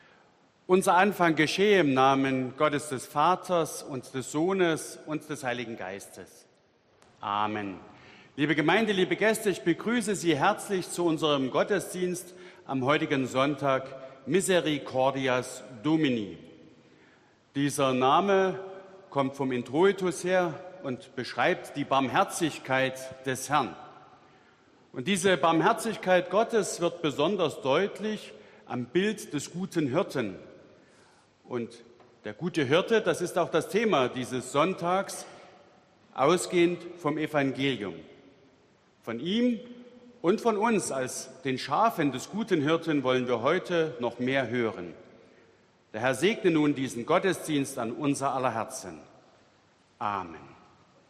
Audiomitschnitt unseres Gottesdienstes vom Sonntag Miserikordias Domini 2022.